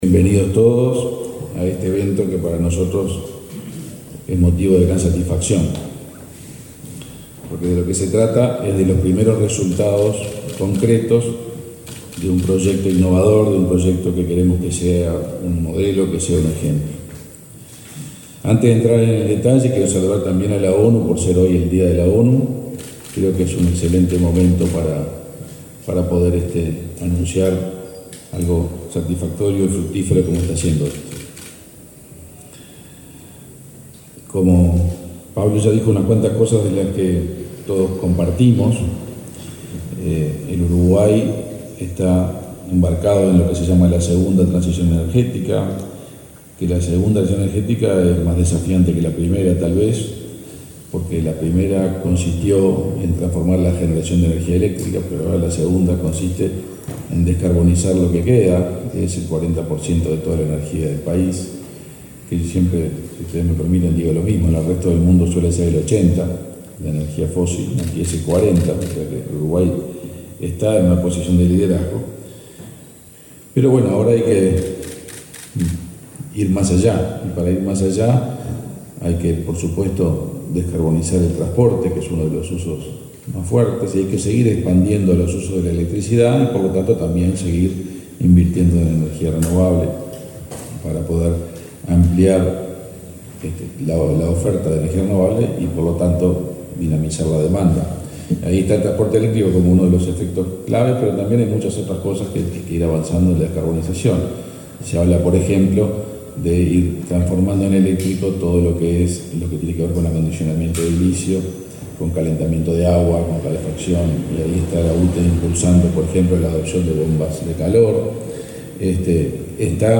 Palabras del ministro de Industria, Omar Paganini
El Ministerio de Industria, Energía y Minería (MIEM) y el Sistema de las Naciones Unidas en Uruguay realizaron una conferencia de prensa en la que presentaron los primeros proyectos aprobados en el marco del Fondo de Innovación en Energías Renovables (REIF, por sus siglas en inglés). El ministro Omar Paganini, destacó la importancia de este plan.